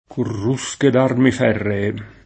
corrusco [korr2Sko] agg.; pl. m. ‑schi — latinismo lett. per «balenante, scintillante» — meno com. corusco [kor2Sko] (pl. m. ‑schi), più vicino all’etimo latino coruscus — es.: corrusche D’armi ferree [